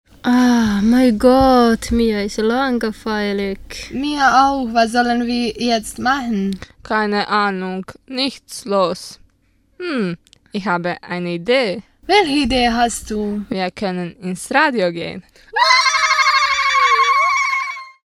Jingles Radio Grenzenlos
Die Jingles von Radio Grenzenlos erzählen alle eine kurze Geschichte mit Geräuschen, Sprache und Musik mit einem Bezug zum Radioprojekt.